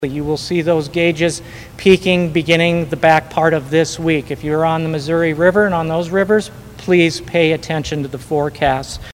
John Benson, Director of Iowa Homeland Security and Emergency Management, stated that his department is doing all it can to support the flood-affected citizens.